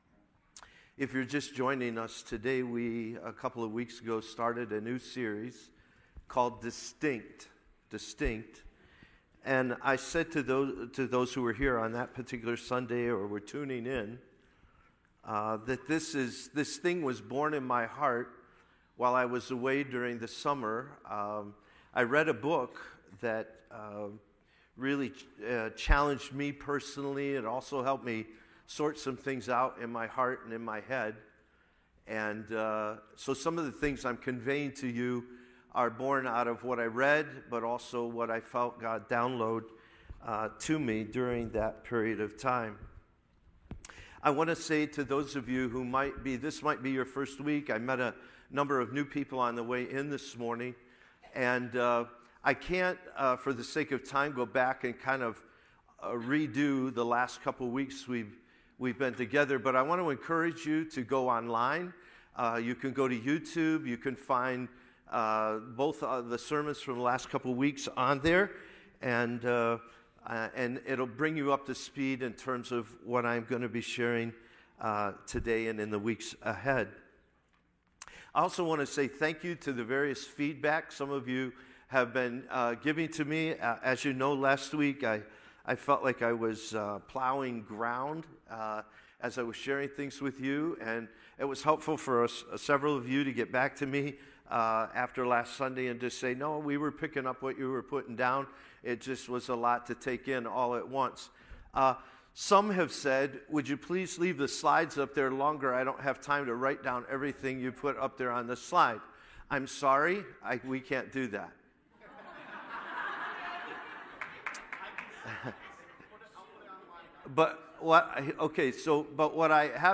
Part 3 Download Sermon Notes